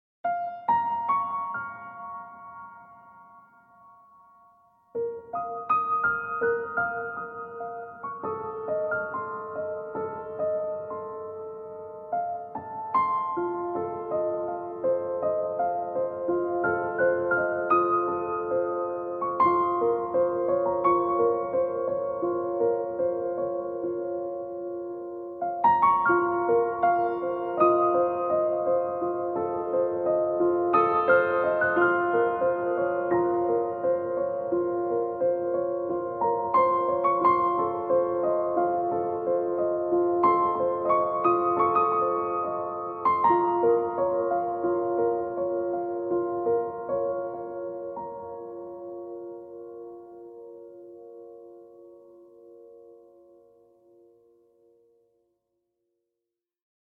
音乐类型：New Age
功地将古典,新时代和地中海风情融合在一起，形成了自己独特的音乐语言和风格。